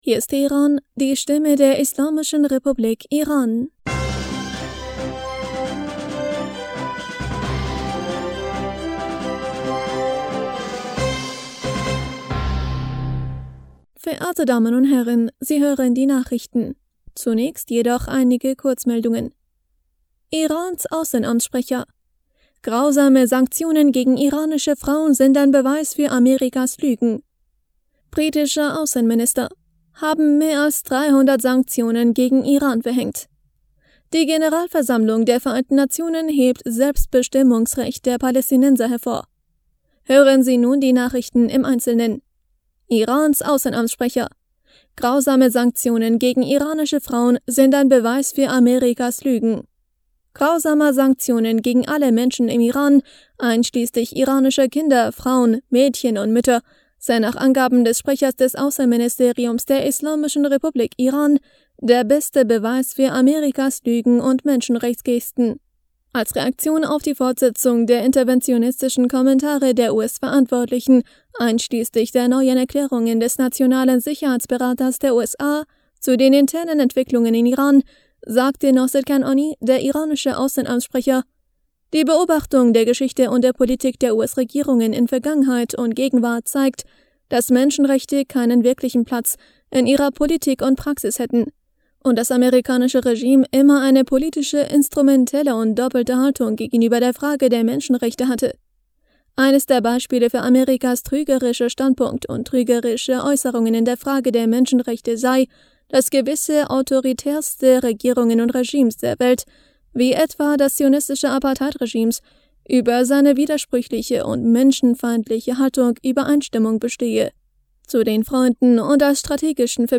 Nachrichten vom 17. Dezember 2022